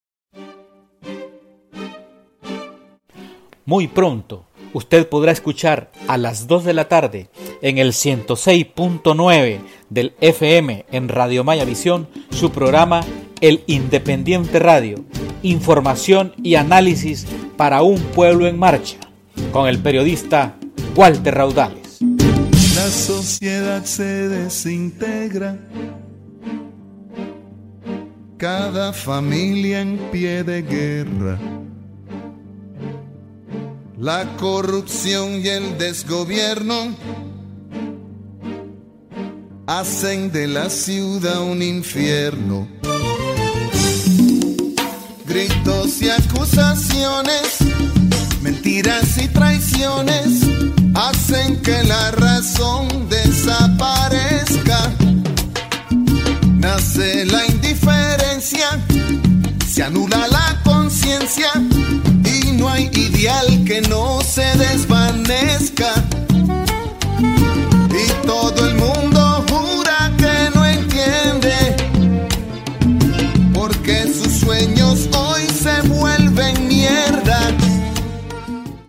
cuña-inderadio.mp3